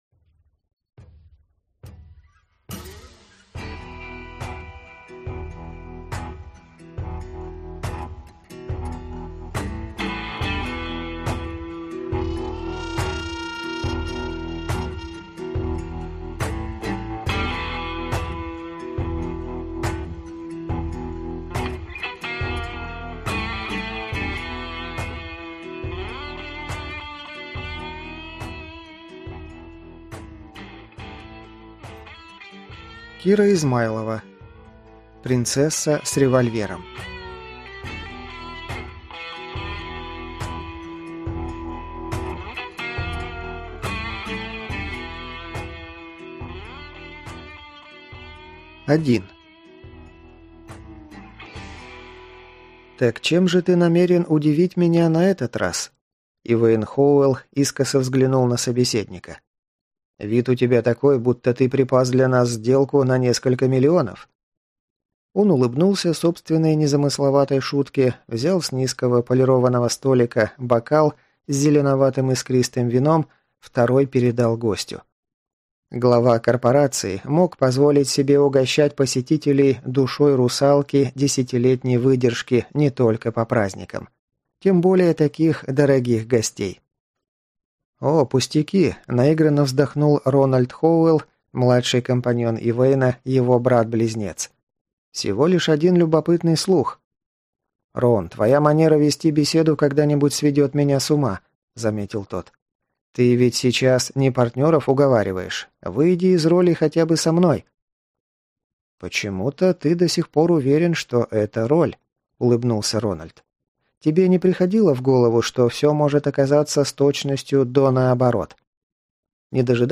Аудиокнига Принцесса с револьвером - купить, скачать и слушать онлайн | КнигоПоиск